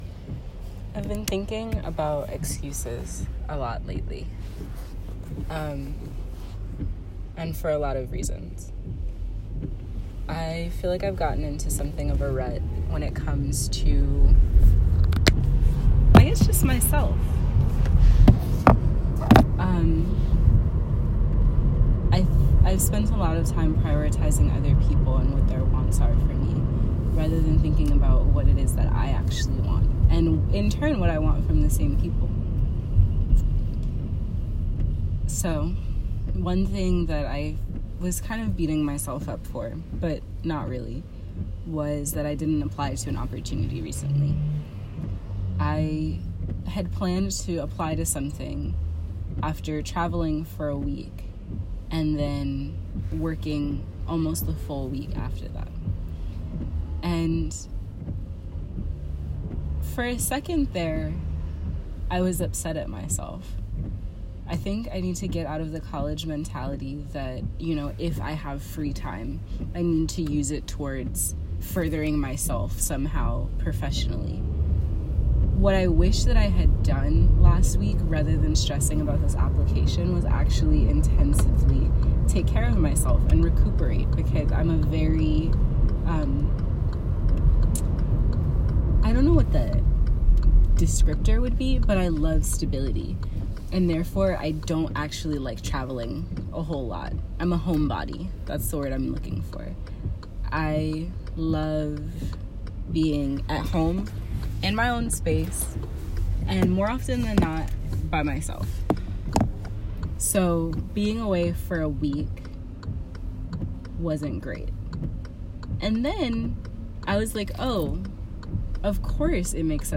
[Recorded from my car.